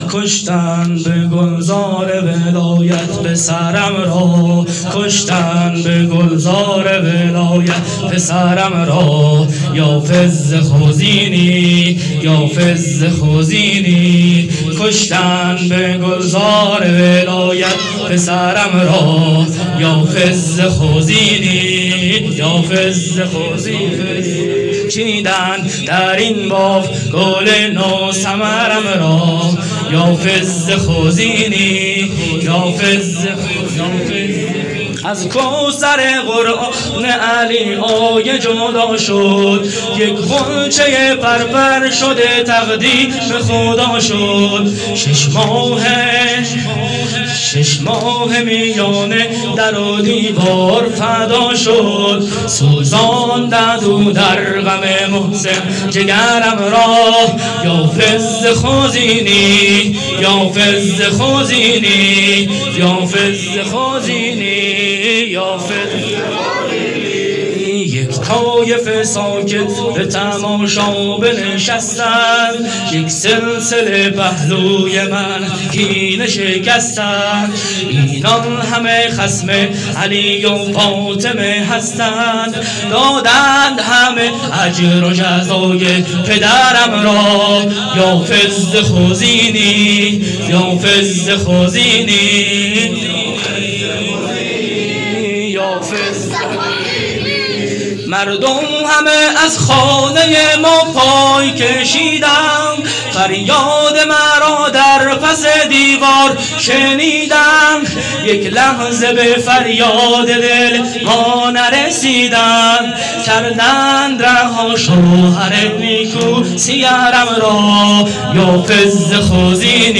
شب اول دهه دوم فاطمیه 1442